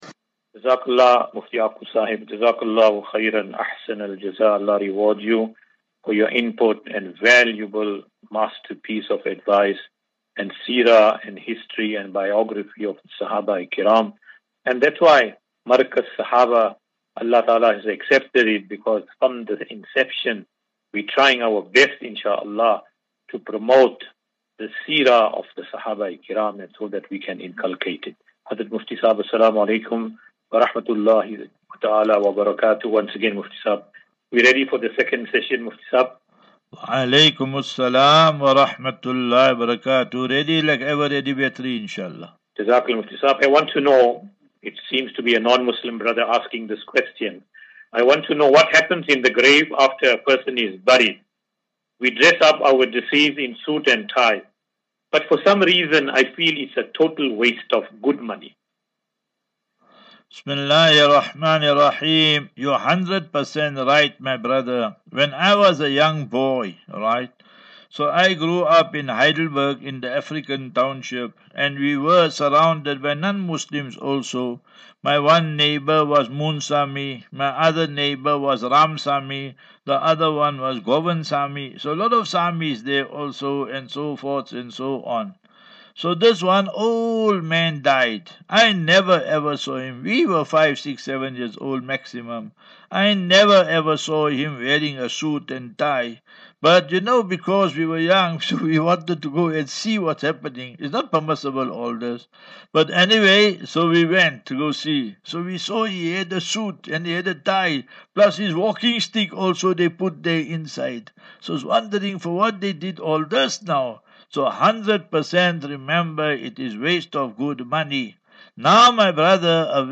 View Promo Continue Install As Safinatu Ilal Jannah Naseeha and Q and A 7 Apr 07 April 2024.